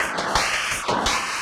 Index of /musicradar/rhythmic-inspiration-samples/170bpm